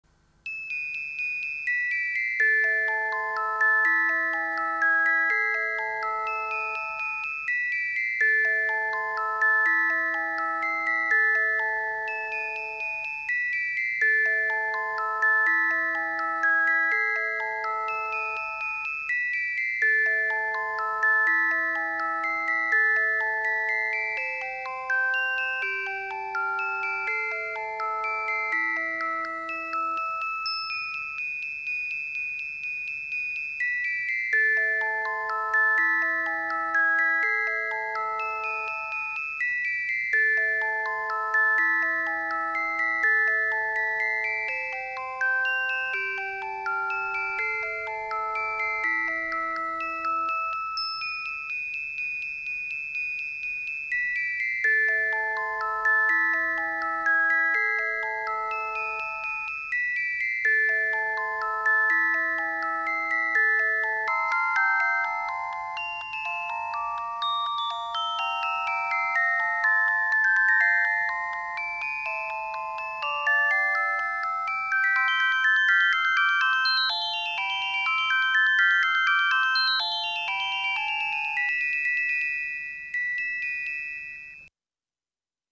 電子オルゴール(ELM)